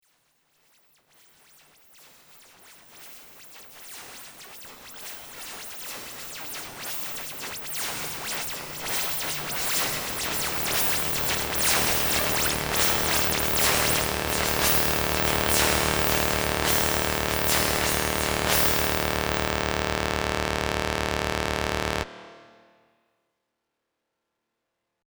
Dance and Soundperformance and Installation